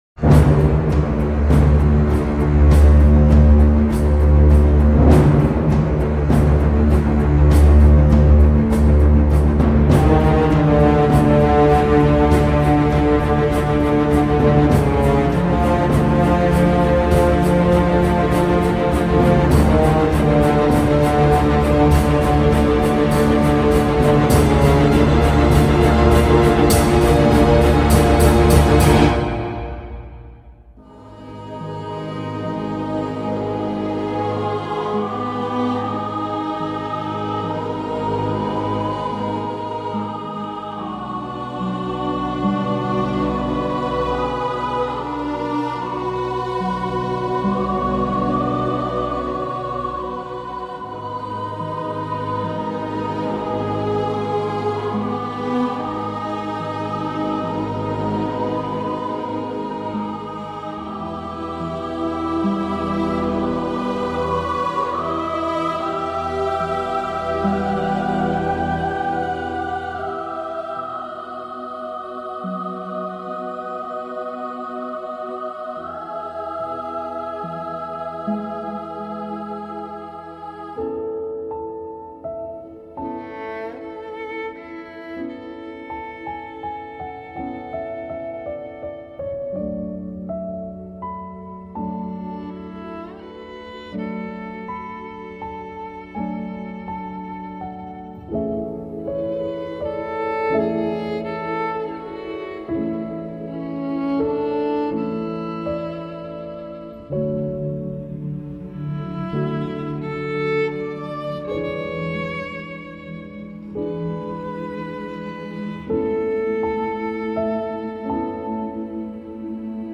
Discours pour orchestre et violon soliste